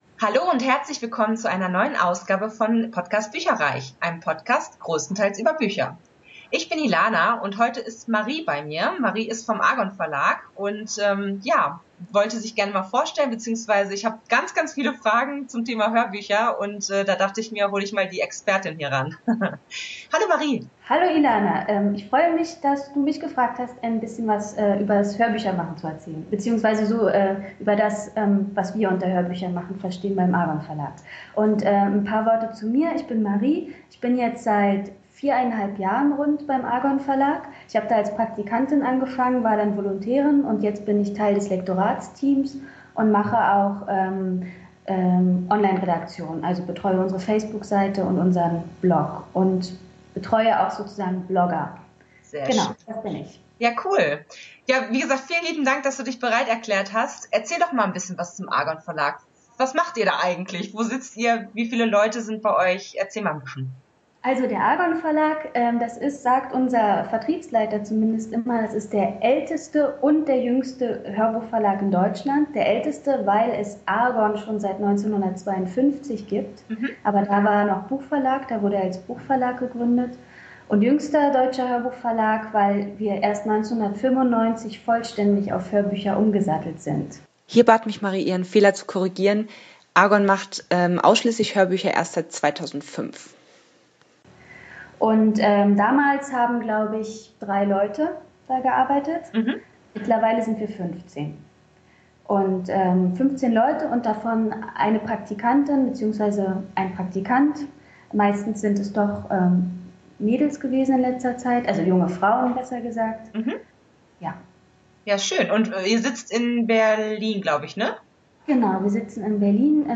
Entschuldigt bitte, dass die Tonqualität leider nicht auf dem üblichen Niveau ist, da das Interview via Skype geführt und aufgenommen wurde.